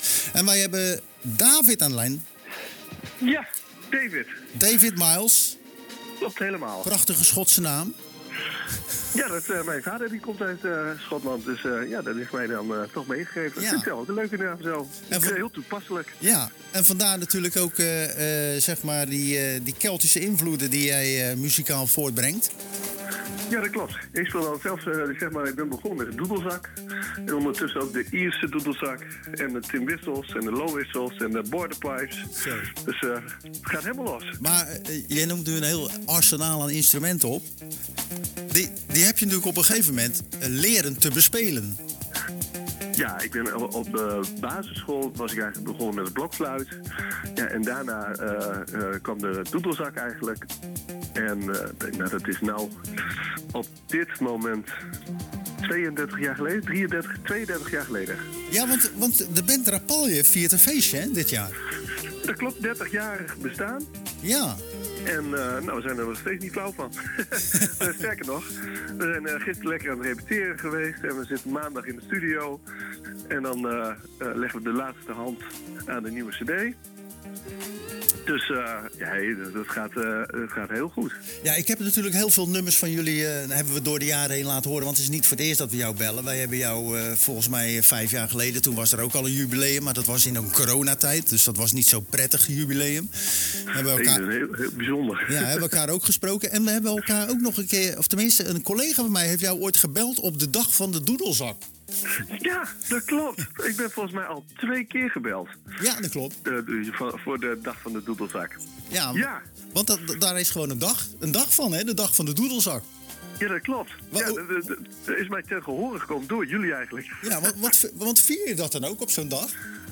De Celtic folkband Rapalje viert dit jaar het 30-jarig jubileum. Daarom belden we hem tijdens de wekelijkse editie van Zwaardvis.